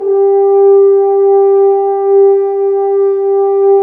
Index of /90_sSampleCDs/Roland L-CDX-03 Disk 2/BRS_F.Horns 2 mf/BRS_FHns Dry mf